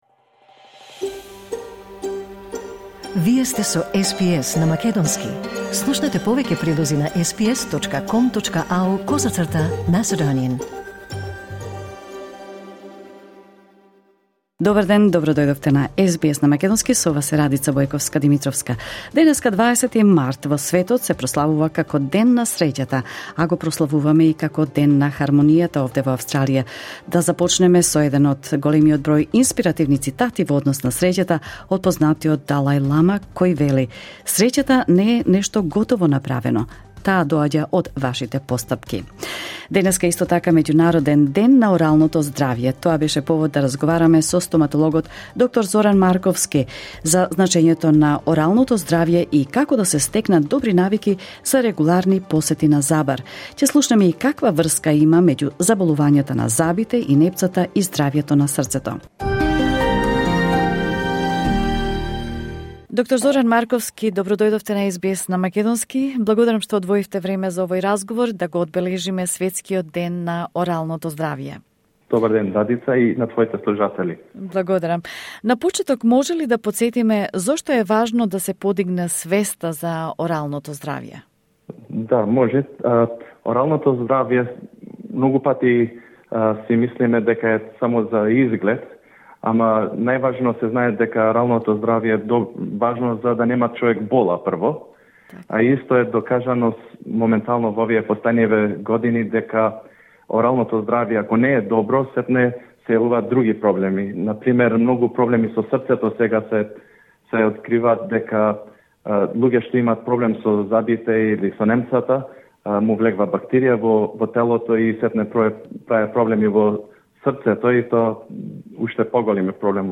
во разговор за СБС на македонски